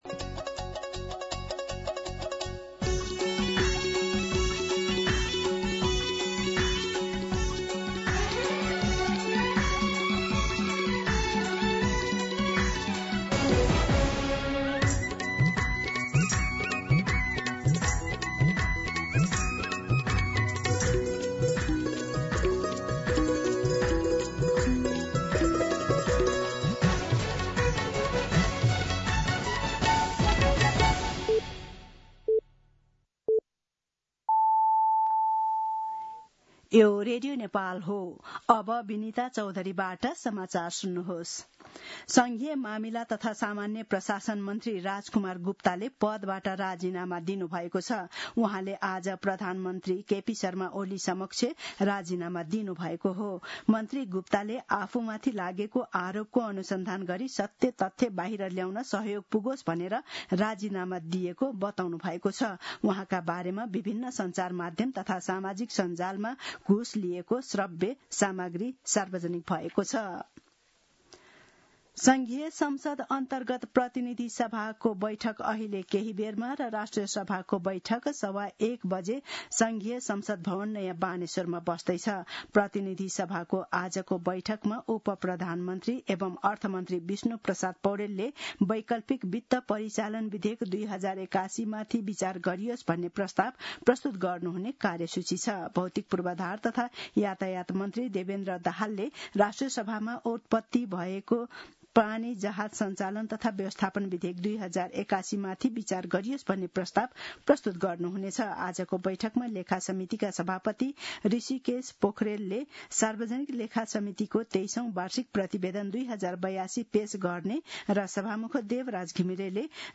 दिउँसो १ बजेको नेपाली समाचार : ३१ असार , २०८२